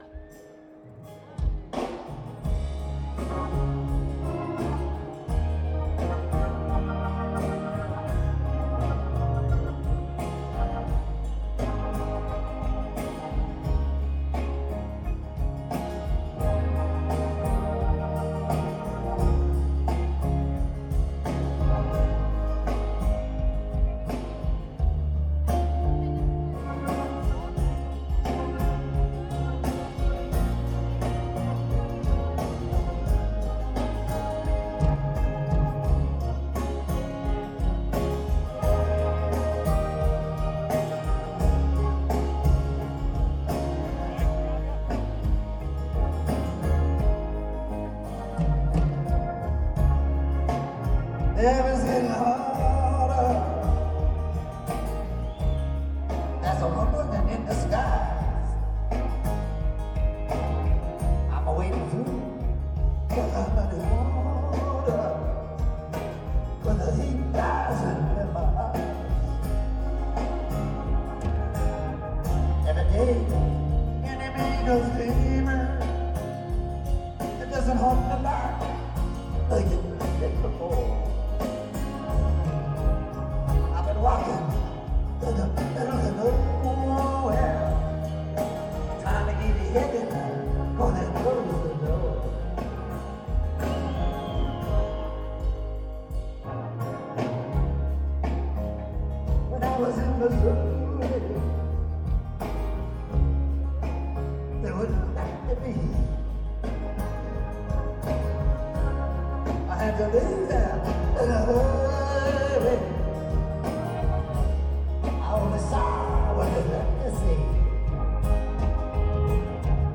Live at the Marquee - Cork, Ireland